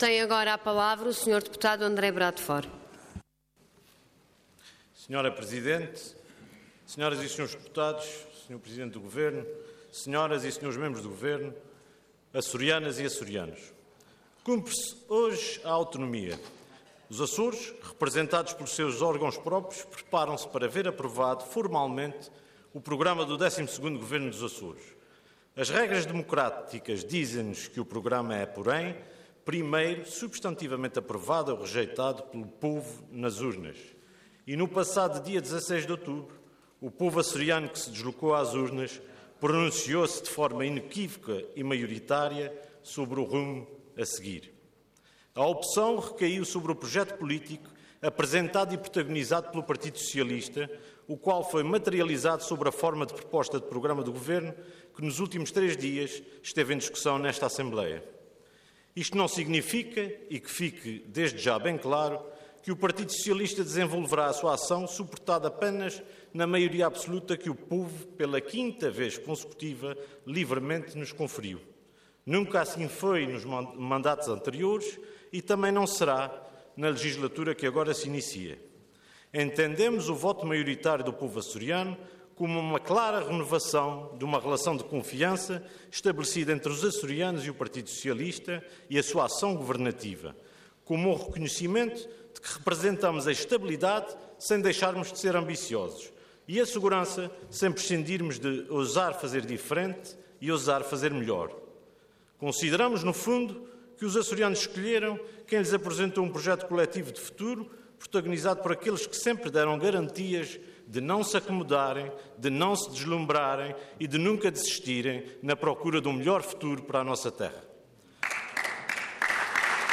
Parlamento online - Intervenção final do Deputado André Bradford do PS - Programa do XII Governo da Região Autónoma dos Açores
Intervenção de Tribuna